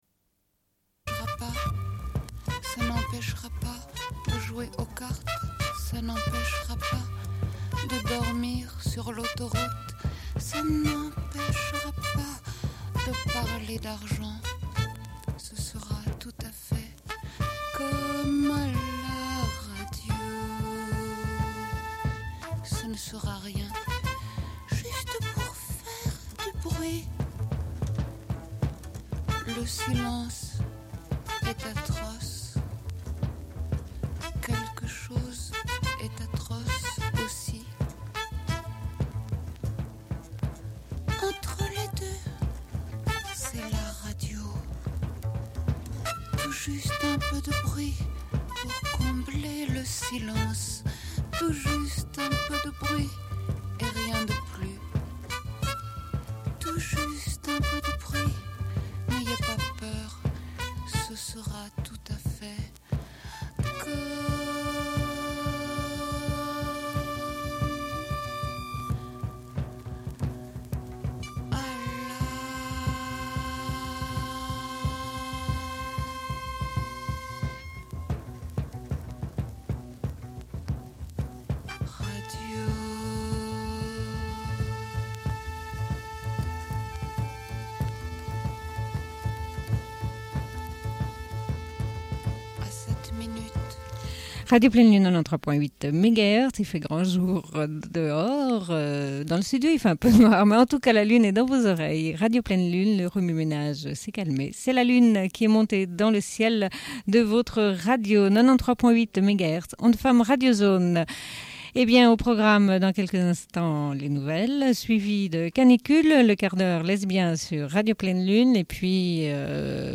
Bulletin d'information de Radio Pleine Lune du 10.06.1992 - Archives contestataires
Une cassette audio, face B29:09